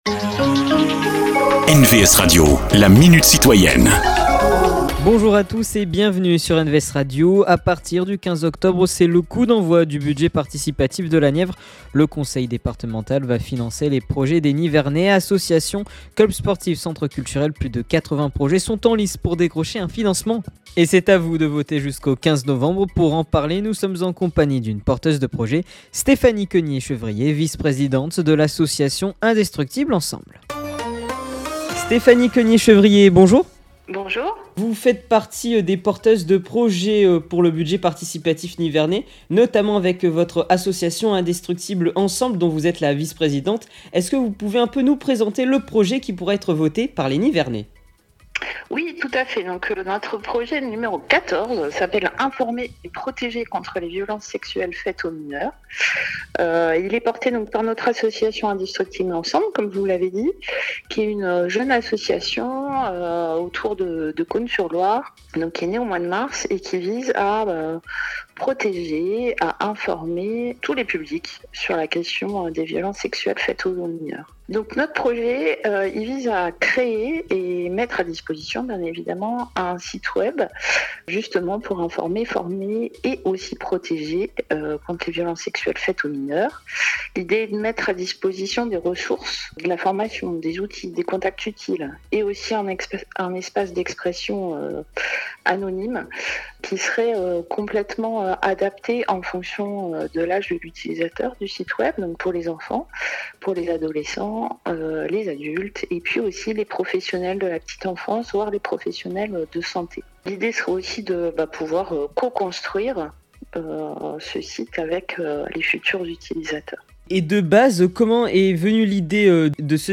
Ils sont élus, maires, sénateurs, députés ou tout simplement citoyen investit dans leurs communes… Rencontre avec ceux qui font l’actualité du territoire.